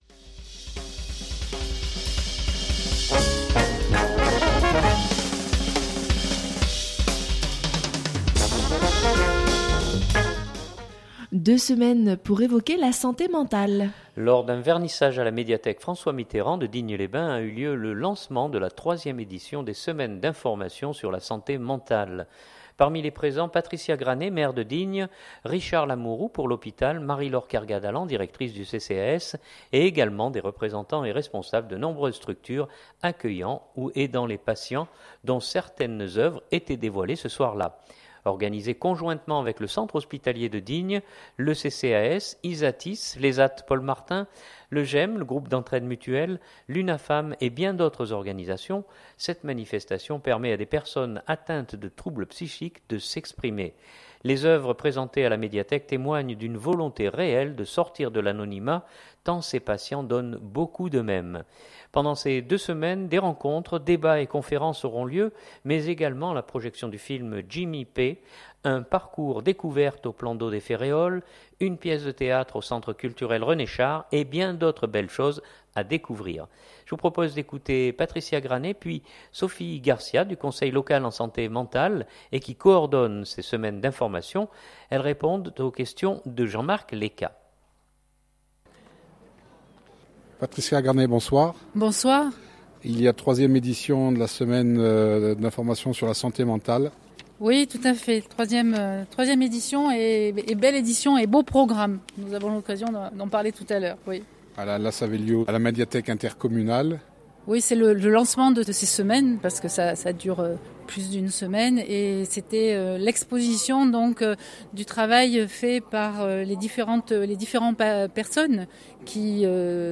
Lors d’un vernissage à la médiathèque François Mitterrand de Digne-les Bains a eu lieu le lancement de la 3ème édition des Semaines d’information sur la santé mentale.